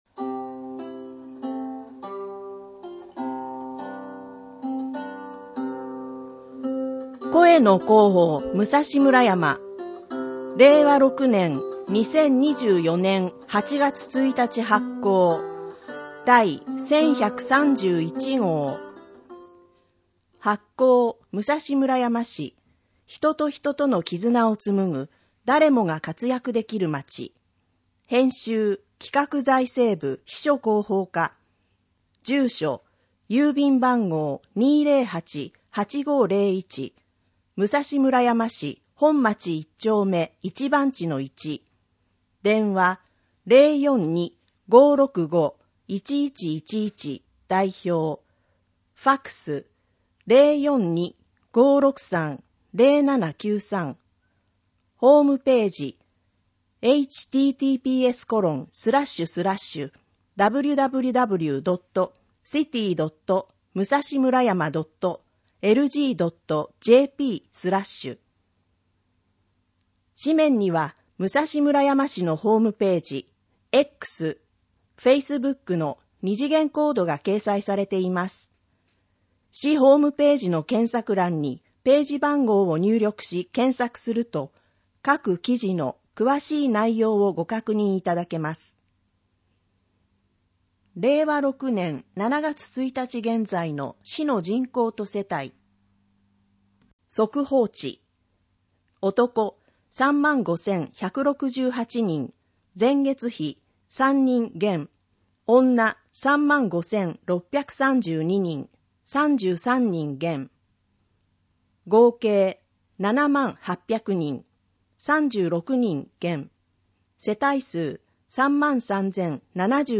このページでは、視覚障害をお持ちの方のために「朗読サークルむらやま」のみなさんが朗読した市報の音声ファイル（MP3）を公開しています。